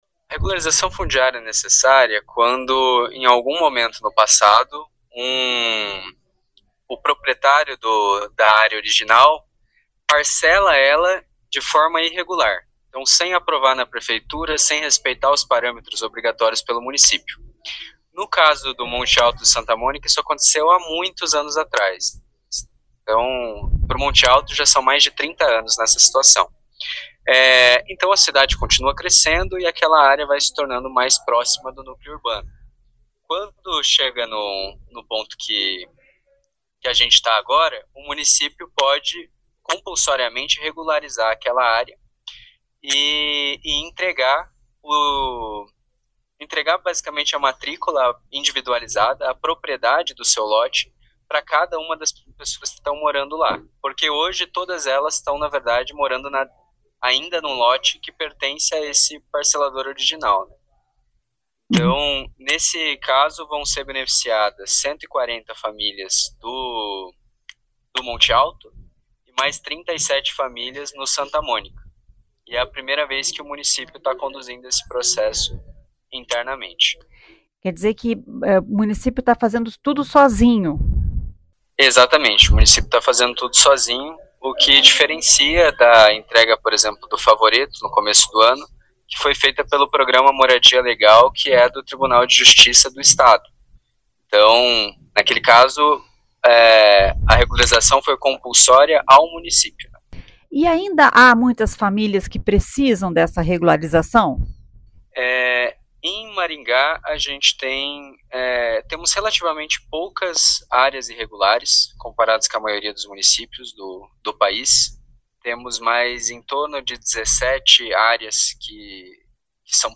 O secretário de Urbanismo e Habitação, Matheus Barros, explica como se dá a regularização fundiária e o benefício que a documentação representa na vida das famílias.